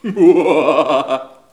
rire-machiavelique_03.wav